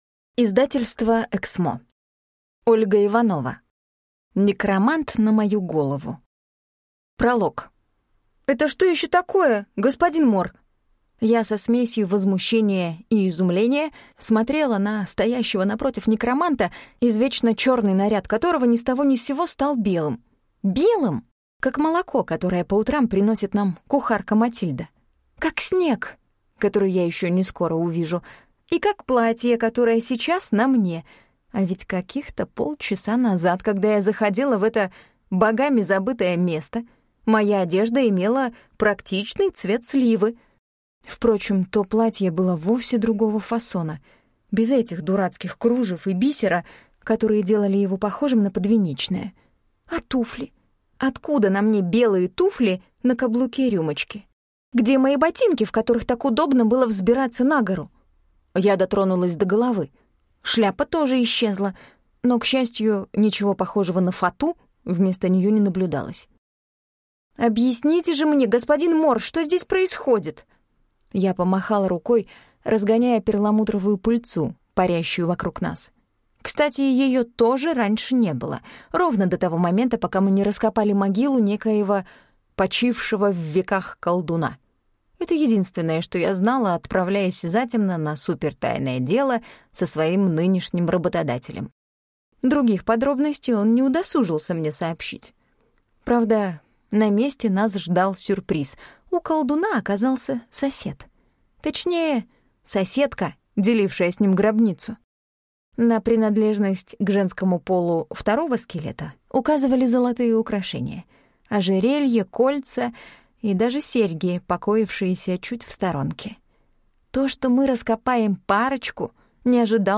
Аудиокнига Некромант на мою голову | Библиотека аудиокниг